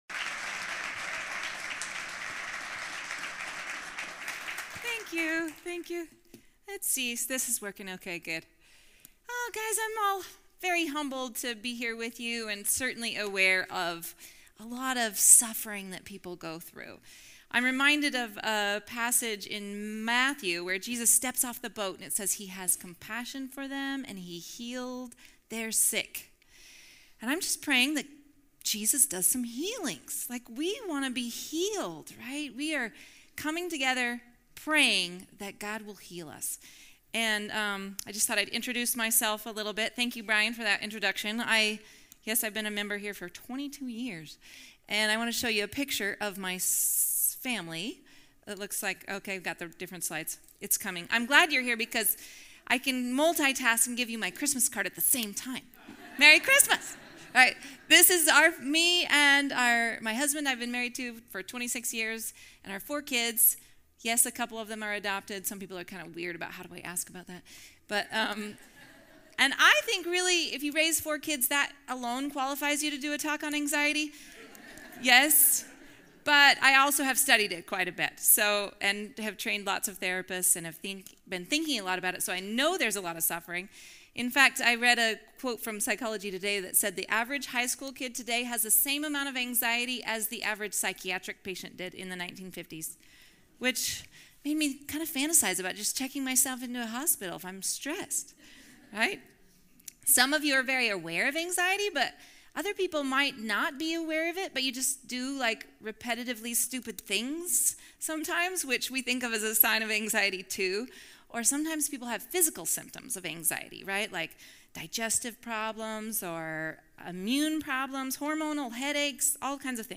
A message from the series "Mental Health Matters."